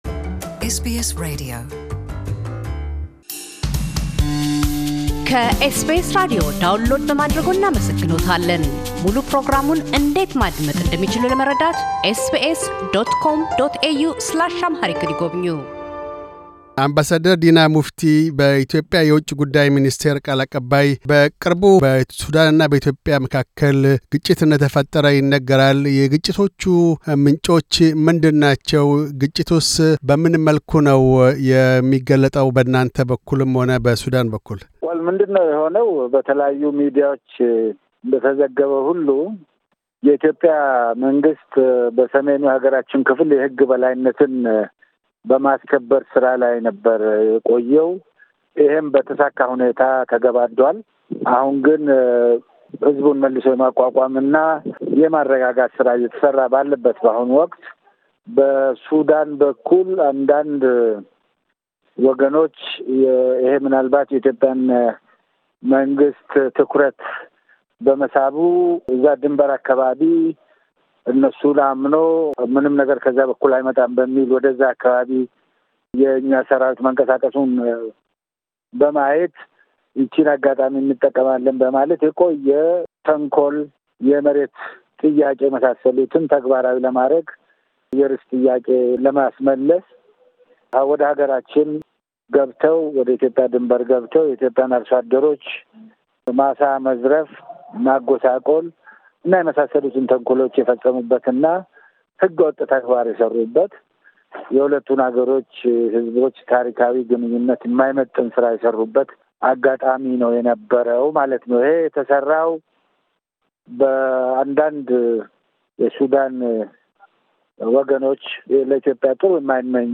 አምባሳደር ዲና ሙፍቲ፤ የኢትዮጵያ ውጭ ጉዳይ ሚኒስቴር ቃል አቀባይ በቅርቡ በኢትዮጵያና በሱዳን ድንበሮች መካከል ስለ ተከስቱ ግጭቶችና ሰላማዊ እልባት ለማበጀት እየተካሄዱ ስላሉ ጥረቶች ይናገራሉ።